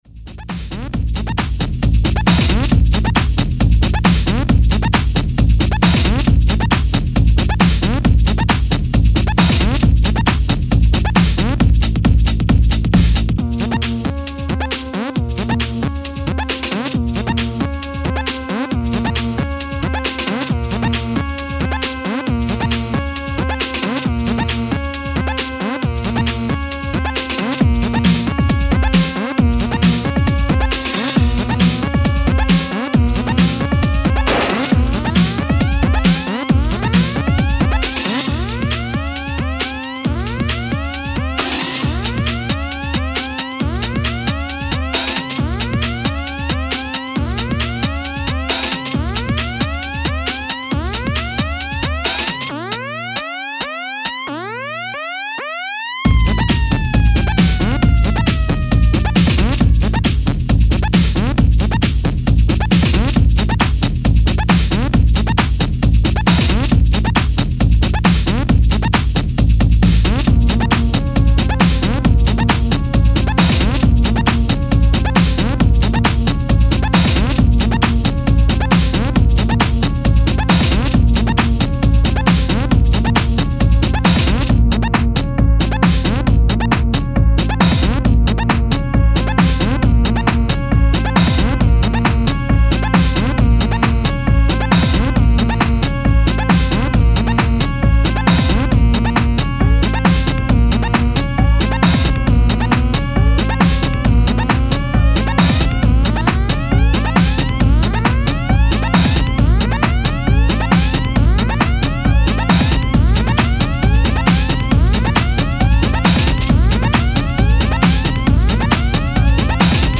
i like this a lot, really funky stuff there mate.